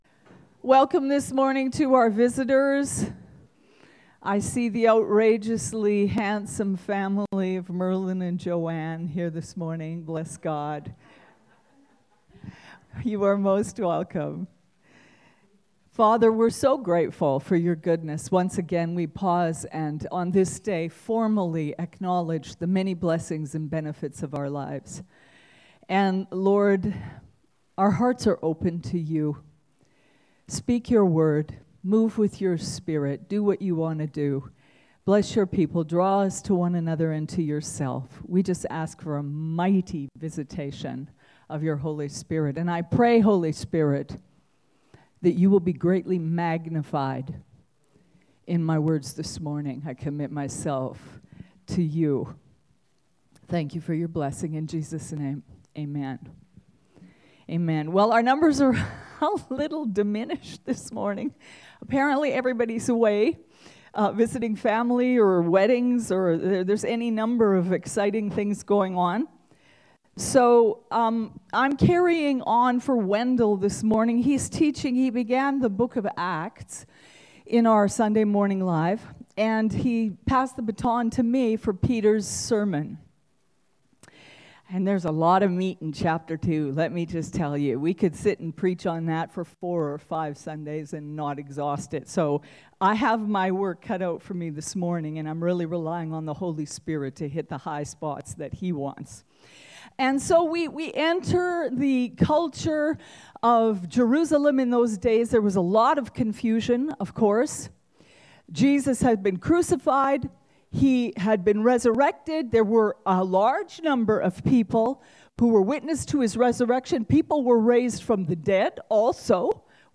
Category: Sunday Morning Live